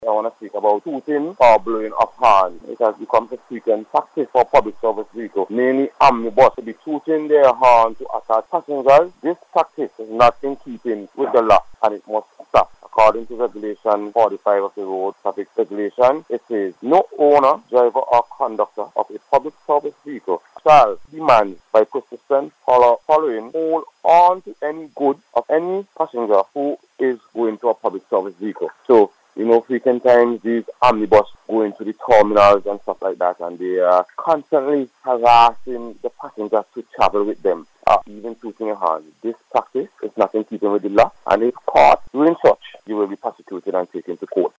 during the Traffic Highlights programme aired on NBC Radio this morning.